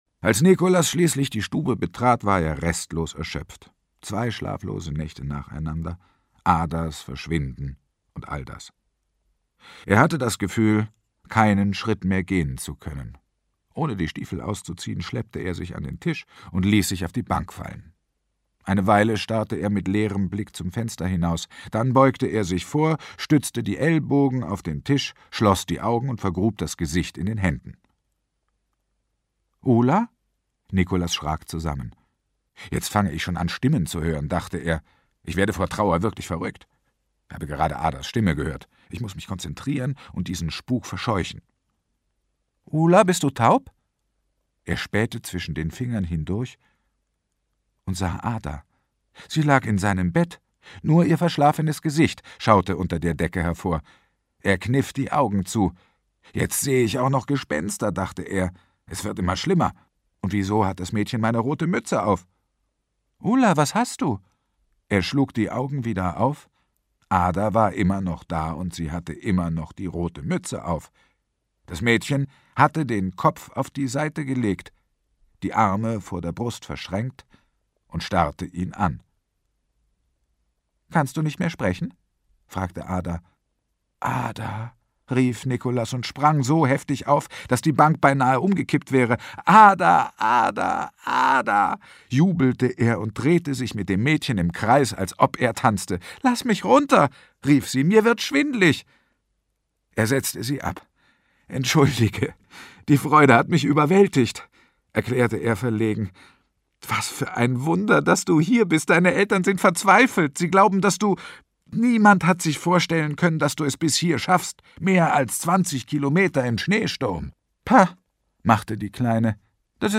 Heikko Deutschmann (Sprecher)
2009 | 3. Auflage, Gekürzte Ausgabe